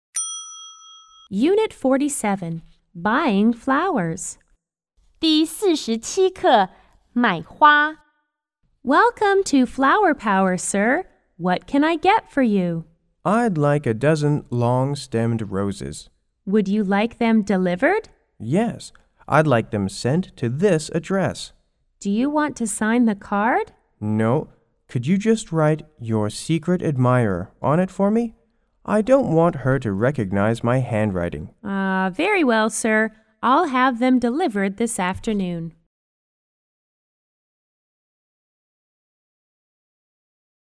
F= Florist C= Customer